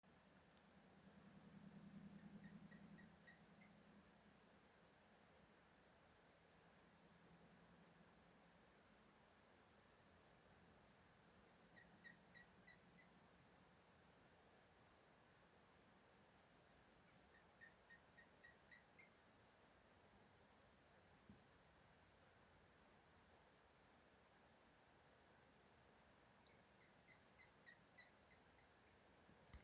Putni -> Pūces ->
Apodziņš, Glaucidium passerinum